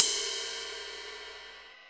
CUP RIDE 2.wav